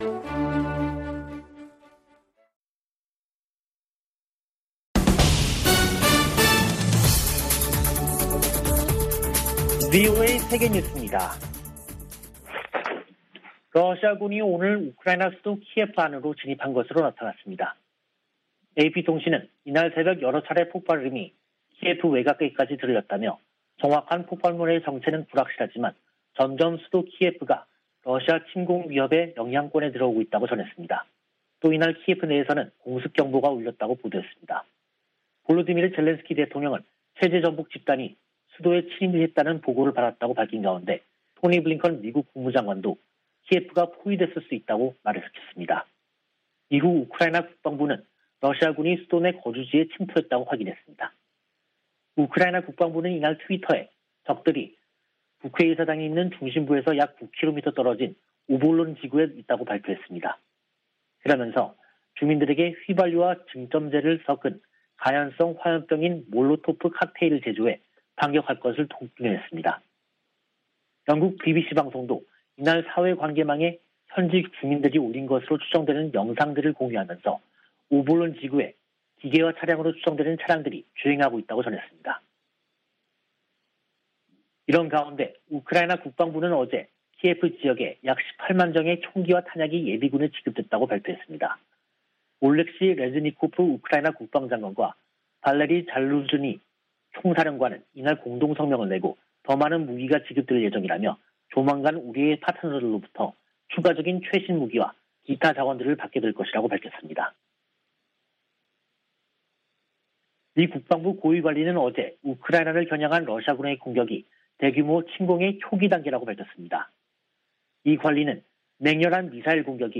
VOA 한국어 간판 뉴스 프로그램 '뉴스 투데이', 2022년 2월 25일 2부 방송입니다. 미 공화당 의원들은 러시아의 우크라이나 무력 침공에 대해 북한 등에 미칠 악영향을 우려하며 강력한 대응을 촉구했습니다. 우크라이나 침공으로 조 바이든 행정부에서 북한 문제가 뒤로 더 밀리게 됐다고 미국 전문가들은 진단했습니다. 유엔은 인도주의 기구들의 대북 송금이 막힌 문제를 해결하기 위해 특별 해법을 논의하고 있다고 밝혔습니다.